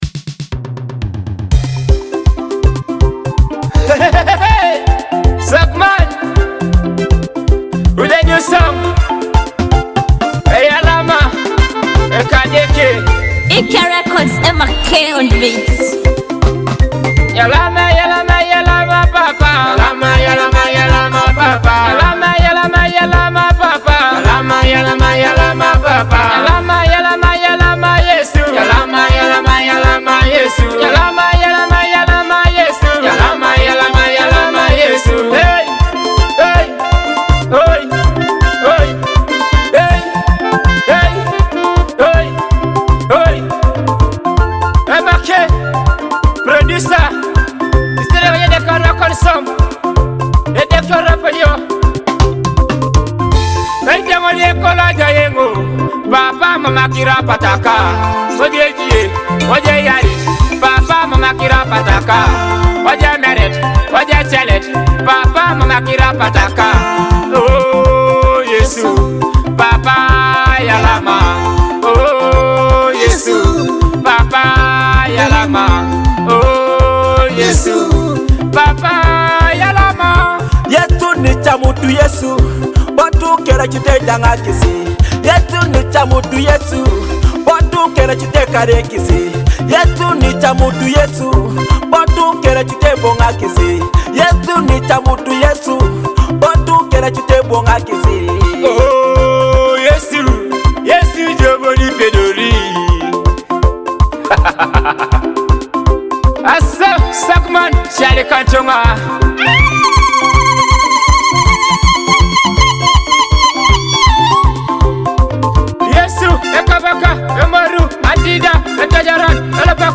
a Teso gospel praise song meaning “Thank You
a heartfelt Teso gospel praise song that means “Thank You